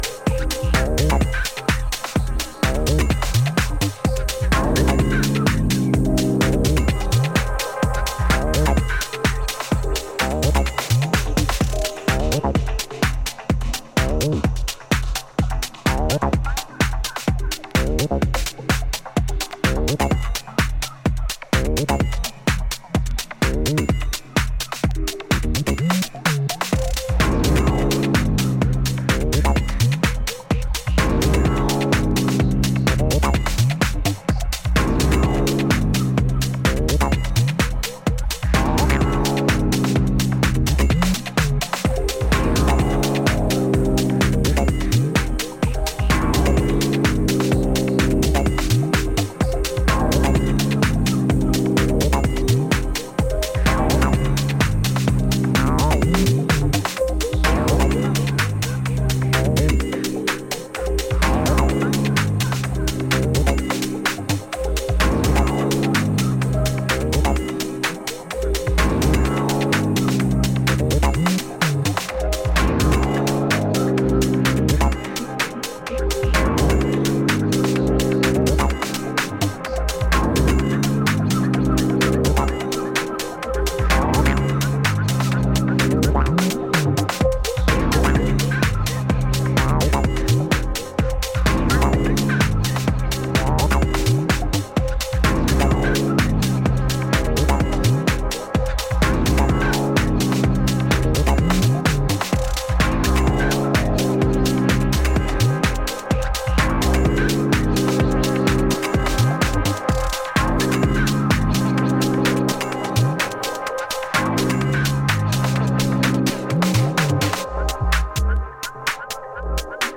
120台の落ち着いたテンポで展開するソリッドなグルーヴはダンサーの気分を高揚させるフロアムードの下地作りにバッチリ。